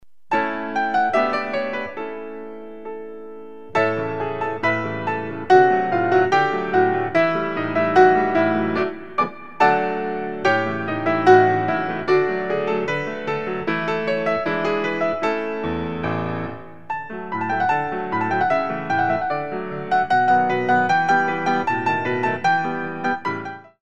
Coda